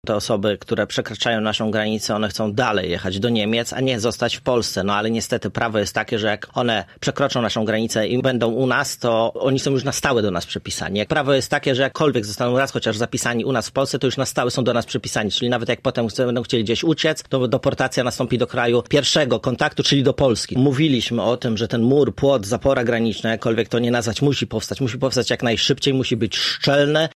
Poseł Konfederacji mówił, że Polska jest krajem tranzytowym, ale przepisy nie są dla nas korzystne:
Krystian Kamiński, który był gościem w Rozmowie po 9, wskazywał też na problemy w obozach dla migrantów.